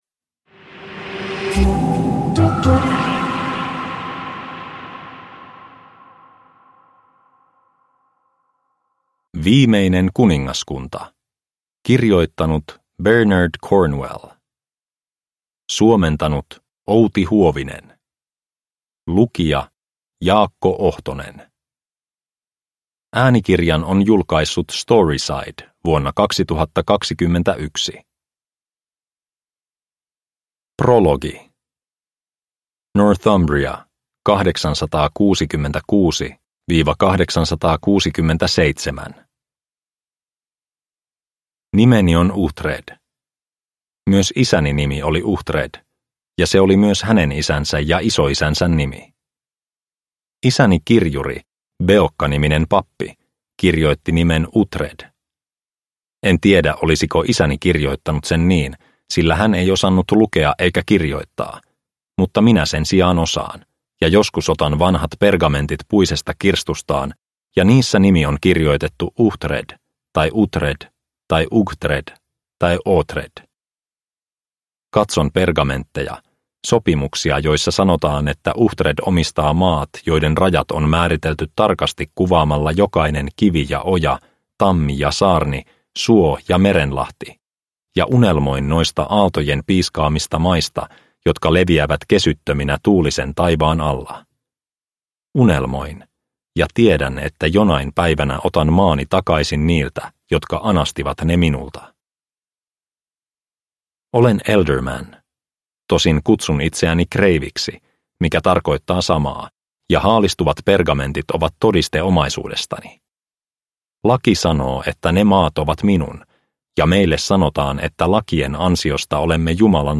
Viimeinen kuningaskunta – Ljudbok – Laddas ner